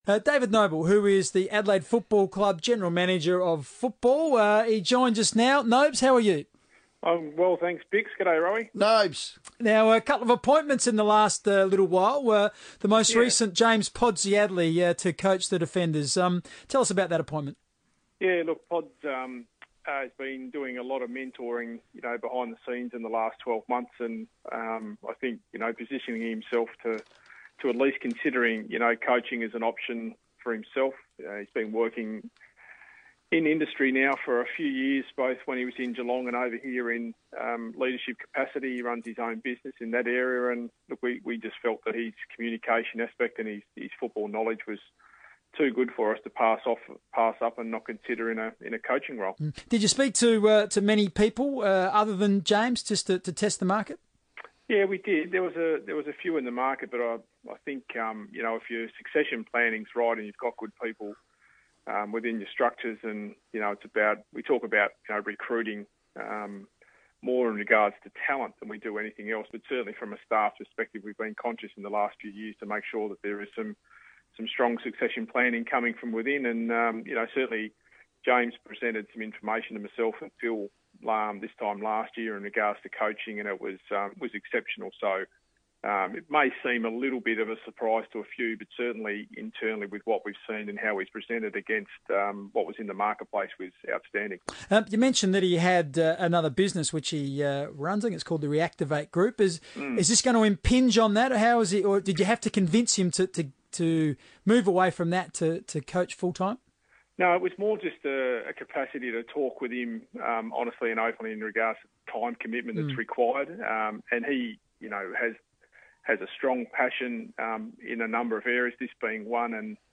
spoke on FIVEaa radio after Adelaide finalised its coaching department for next season